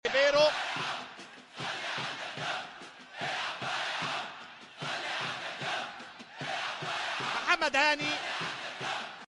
دم جمهور الاهلي